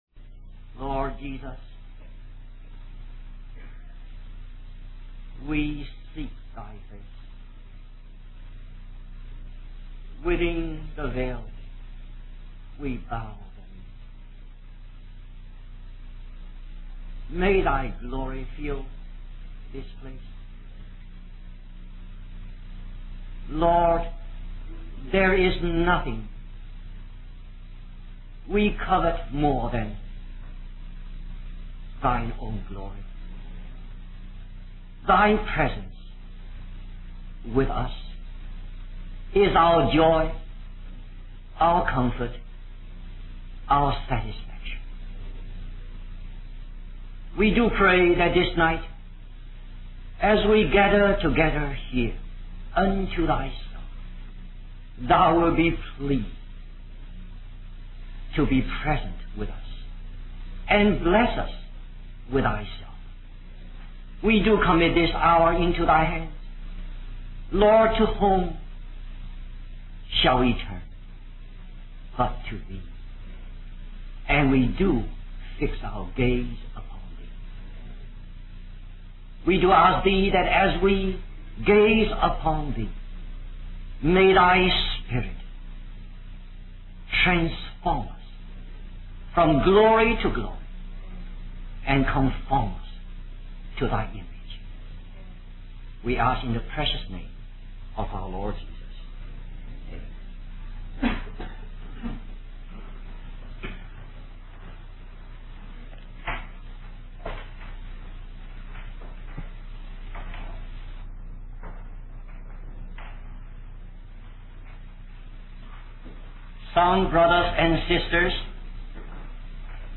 A collection of Christ focused messages published by the Christian Testimony Ministry in Richmond, VA.
Wabanna (Atlantic States Christian Convocation)